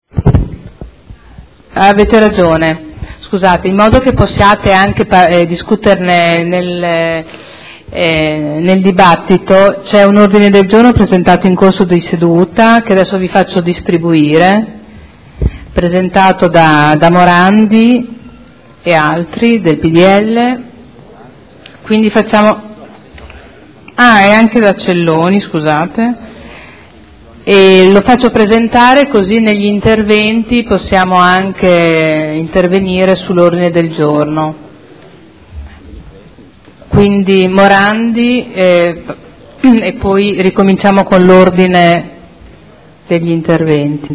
Seduta del 18/06/2012. Comunicazione su Ordine del Giorno presentato durante la Seduta